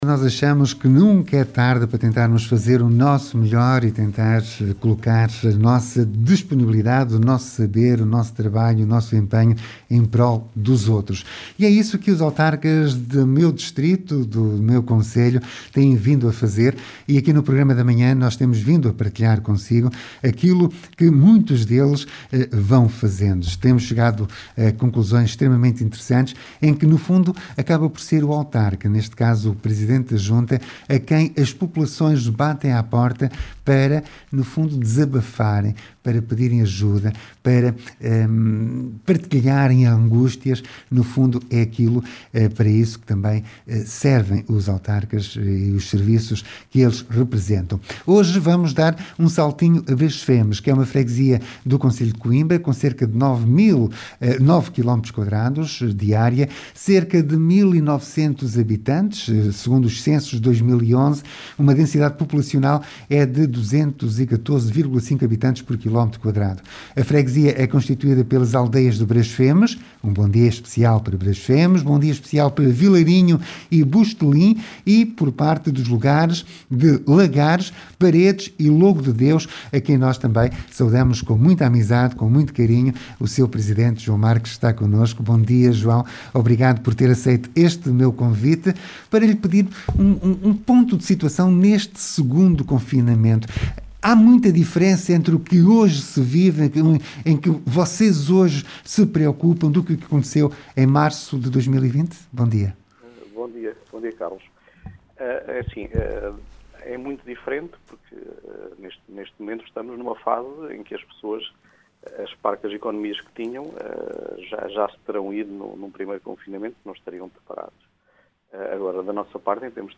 Como está a freguesia de Brasfemes a lidar com esta segunda fase de confinamento? João Marques, presidente da Junta, explicou o enorme trabalho da Comissão Social da Freguesia e abordou vários assuntos relativos à gestão do Município.